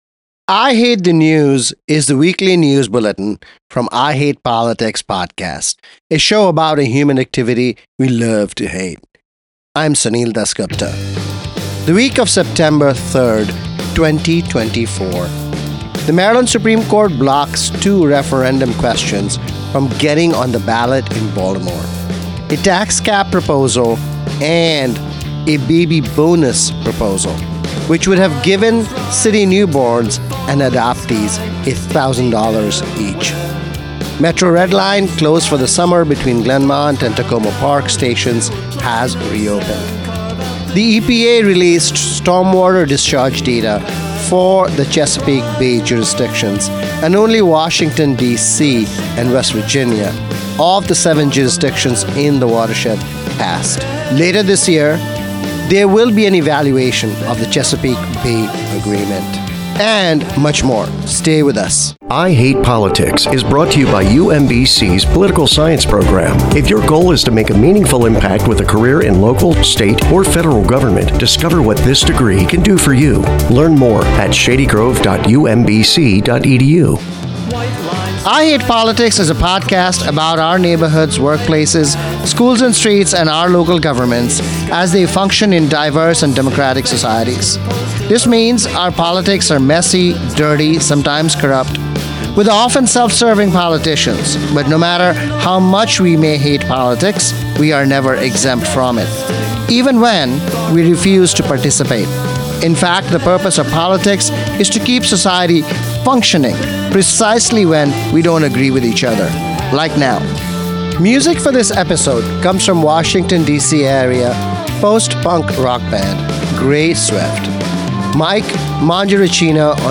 Episode · I Hate Politics Podcast · The weekly news analysis from I Hate Politics: Maryland Supreme court blocks the “baby bonus” and tax cap referendum questions from the ballot. Washington Metro reopens Red Line between Glenmont and Takoma Park stations after summer closure.